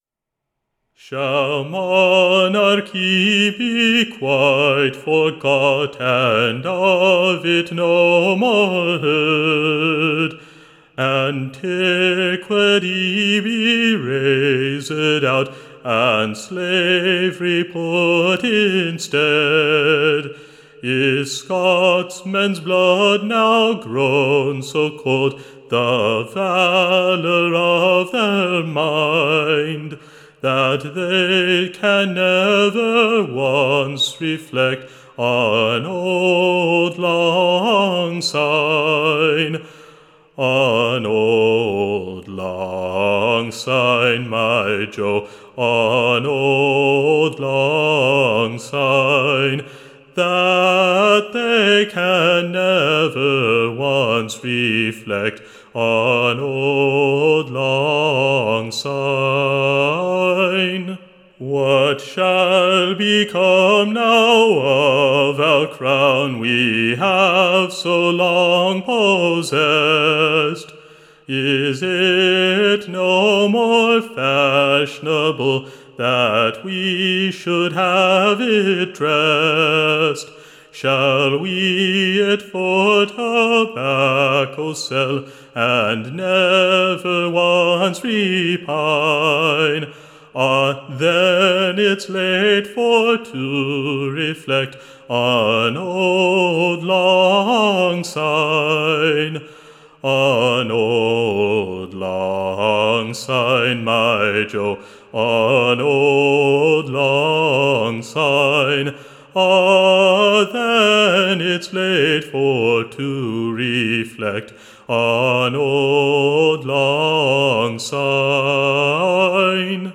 EBBA 31328 - UCSB English Broadside Ballad Archive